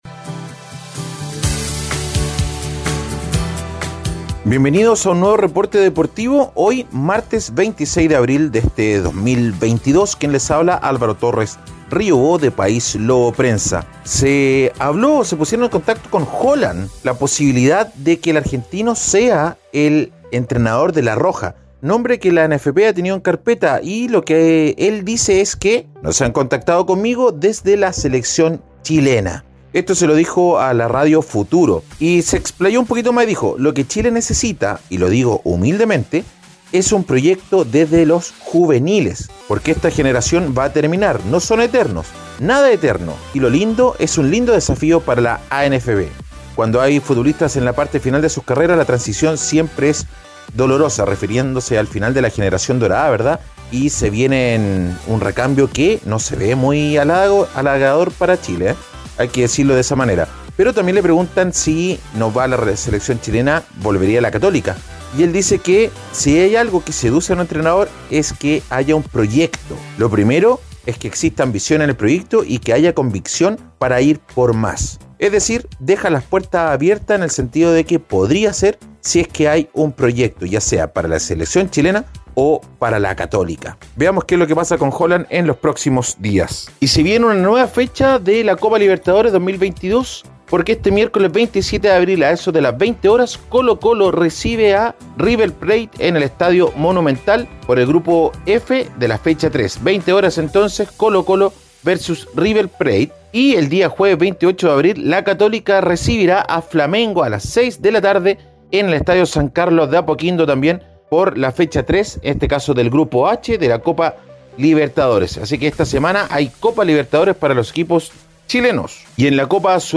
Reporte Deportivo ▶ Podcast 26 de abril de 2022